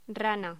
Locución: Rana
locución
Sonidos: Voz humana